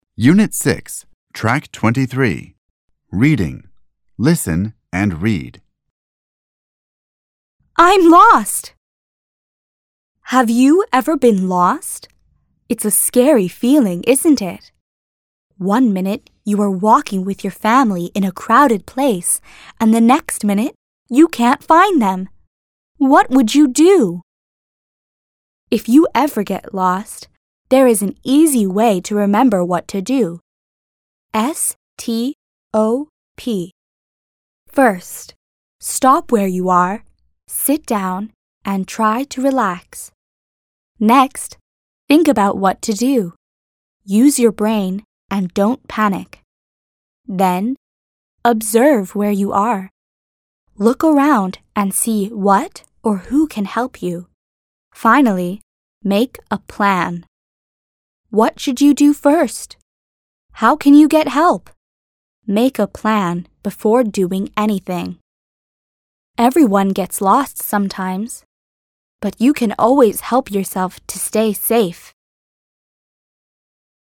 این مجموعه با لهجه ی  امریکن و توسط نویسندگانی چون Beat Eisele, Catherine Yang Eisele, Stephen M. Hanlon, Rebecca York Hanlon به رشته تحریر درآمده است و همچنین دارای سطح بندی از مبتدی تا پیشرفته می باشد و سراسر مملو از داستان های جذاب با کاراکتر های دوست داشتنی می باشد که یادگیری و آموزش زبان انگلیسی را برای کودکان ساده و لذت بخش تر خواهد کرد.